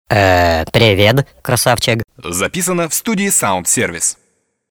Сотрудники студии звукозаписи «СаундСервис» в честь праздника создали и записали несколько приколов и шуток.